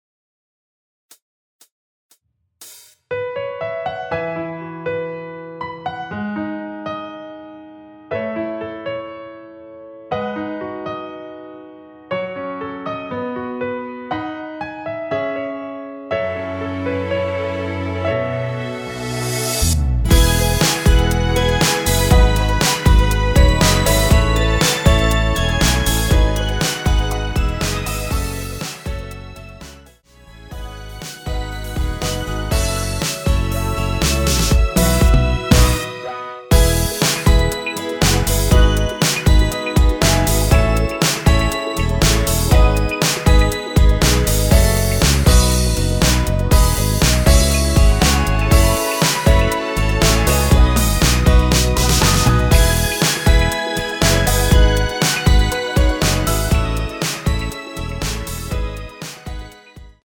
여성분이 부르실수 있는 키로 제작 하였습니다.(미리듣기 참조)
앞부분30초, 뒷부분30초씩 편집해서 올려 드리고 있습니다.
중간에 음이 끈어지고 다시 나오는 이유는